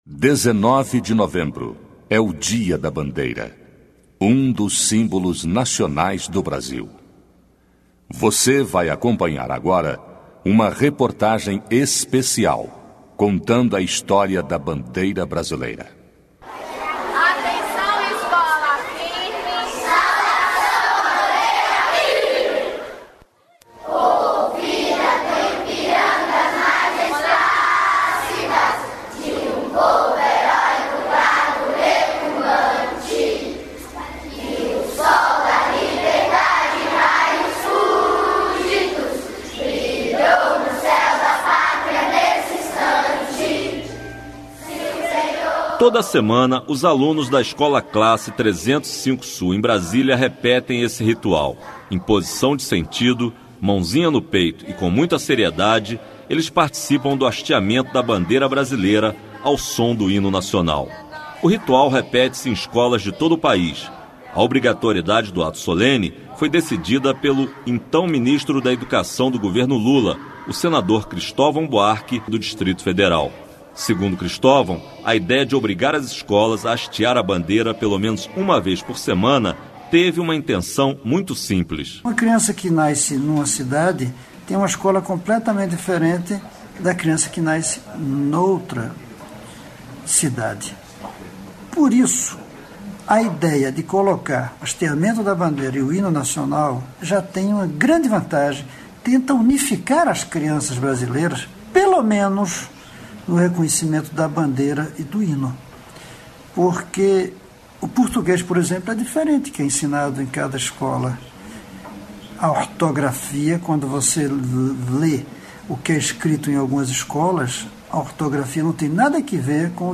Reportagem especial sobre a bandeira do Brasil